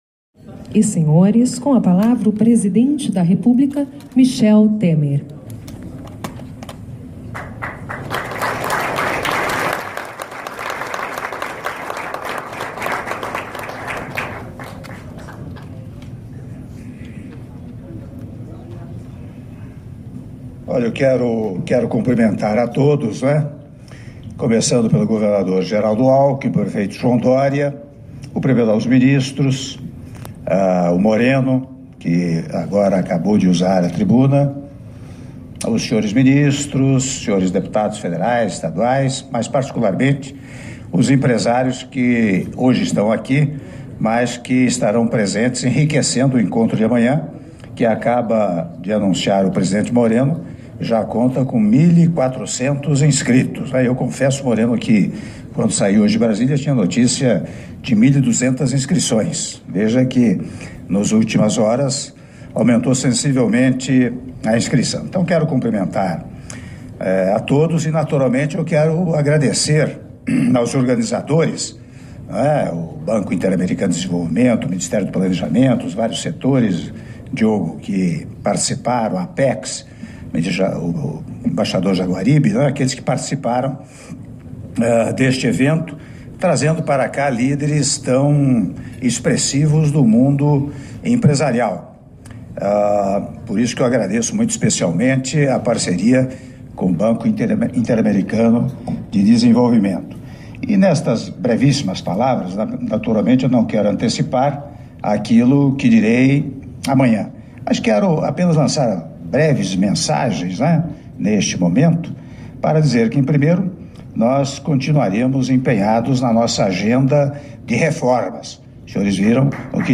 Áudio do discurso do Presidente da República, Michel Temer, durante jantar por ocasião do Fórum de Investimentos Brasil 2017 - São Paulo/SP (05min59s)